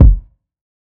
TM AmbitionKick[raw].wav